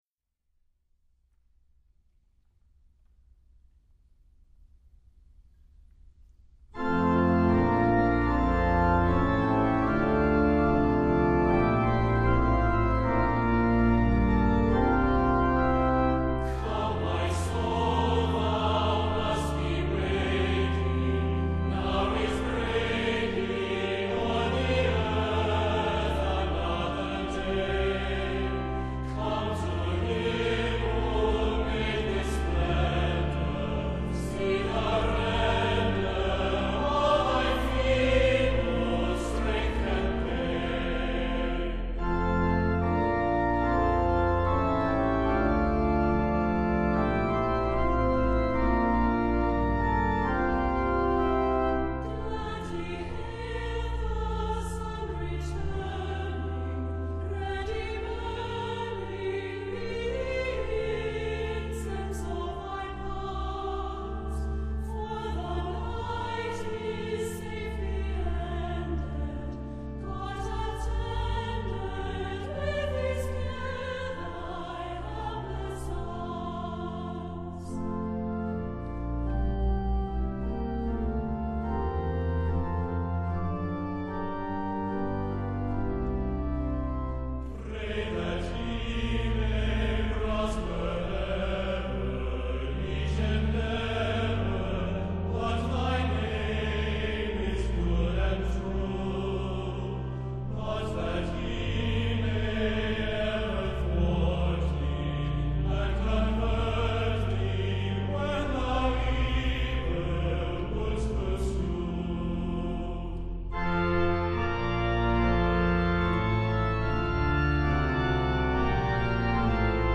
Choral Series